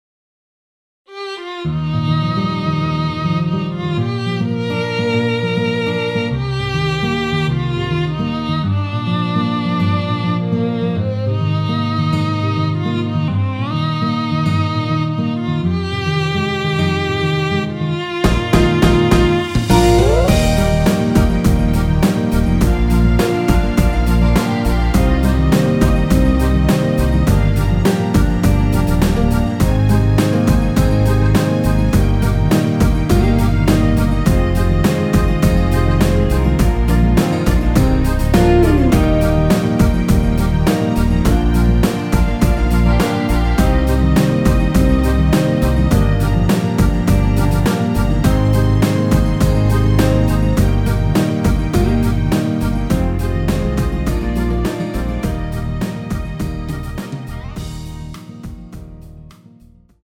원키에서(-3)내린 MR입니다.
Eb
앞부분30초, 뒷부분30초씩 편집해서 올려 드리고 있습니다.
중간에 음이 끈어지고 다시 나오는 이유는